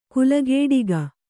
♪ kulagēḍiga